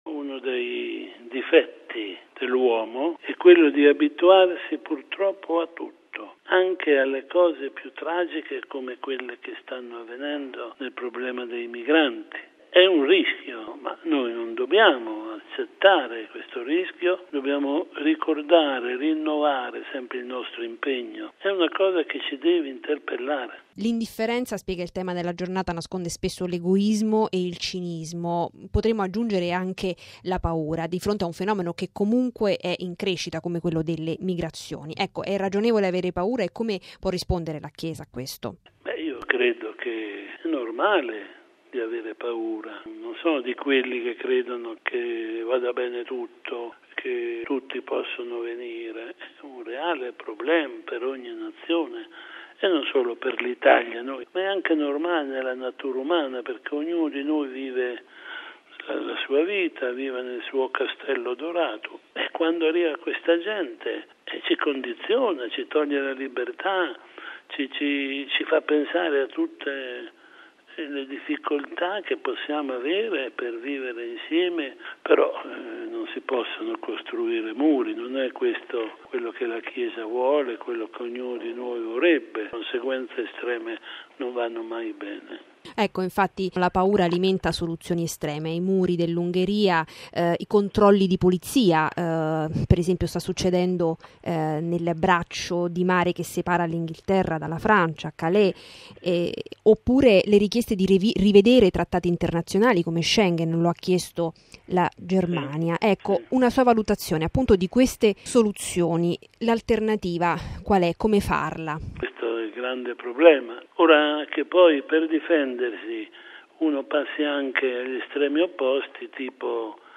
Logo 50 Radiogiornale Radio Vaticana